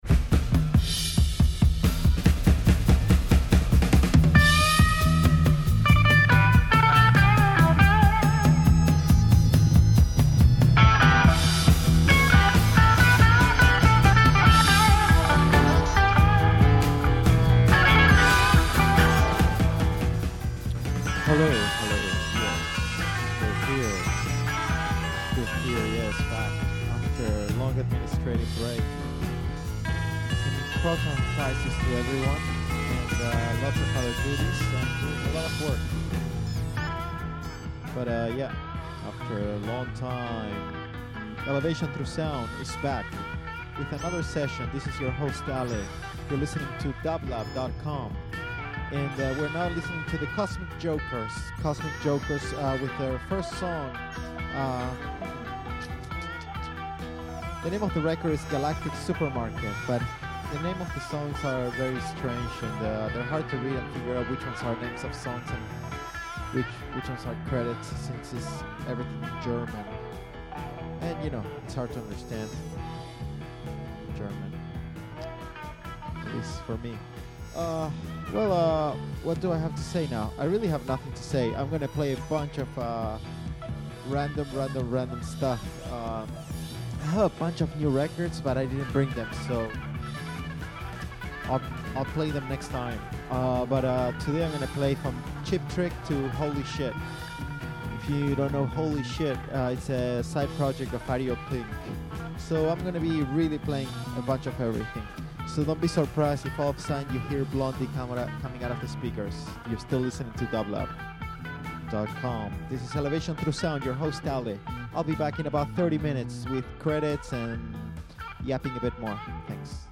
Disco Electronic Rock Soul Techno